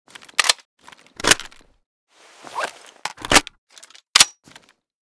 aug_reload_empty.ogg